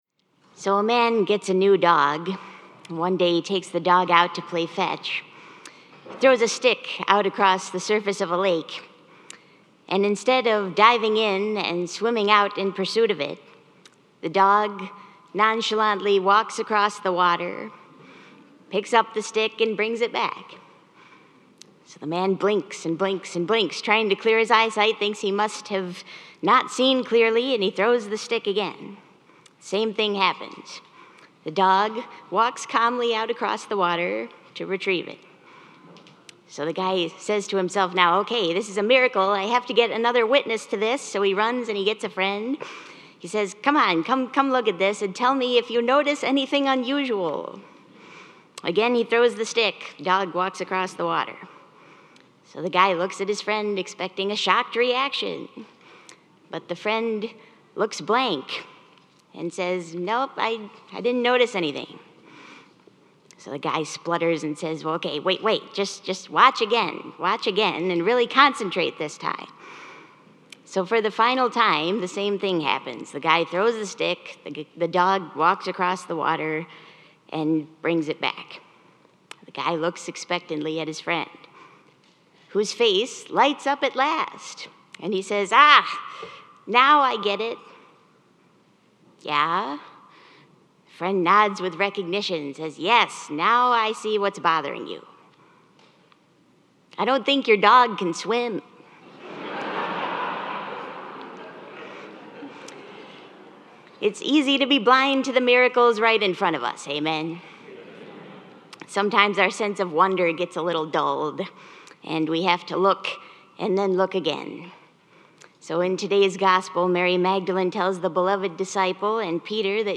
Easter Sunday Liturgy 2022